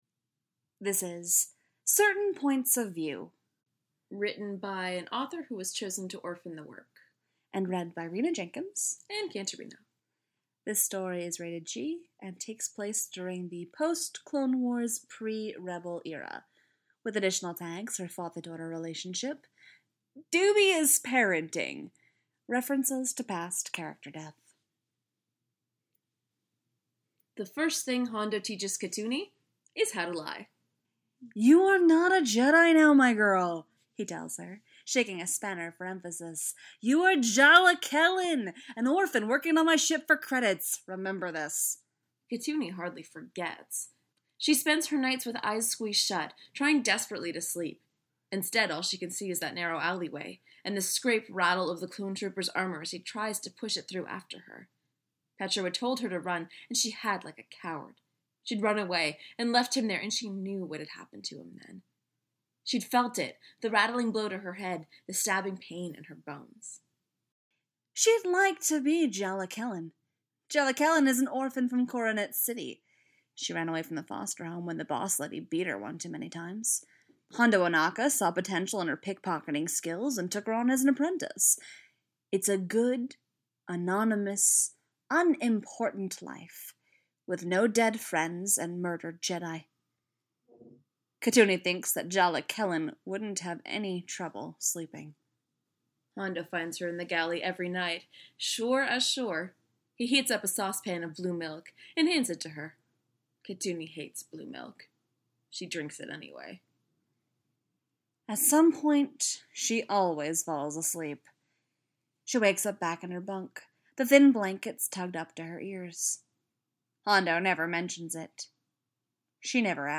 info|includes freetalk
collaboration|two voices